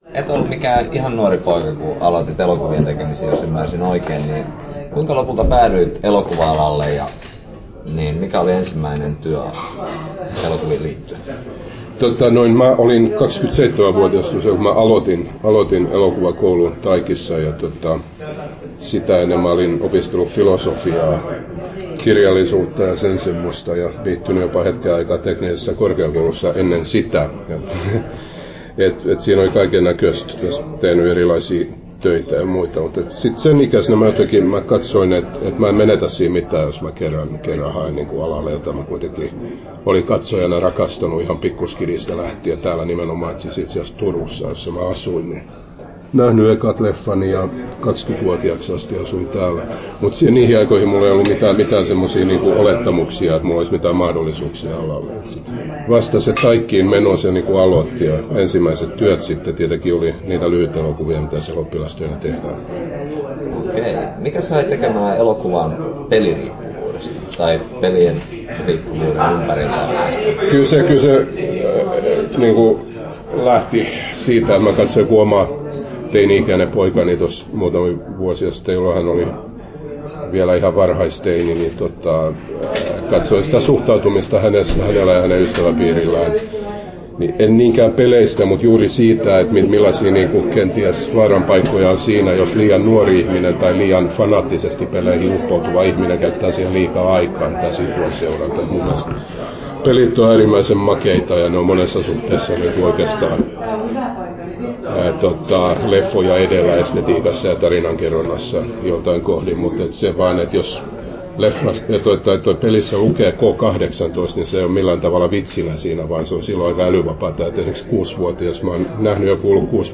10'05" Tallennettu: 11.1.2012, Turku Toimittaja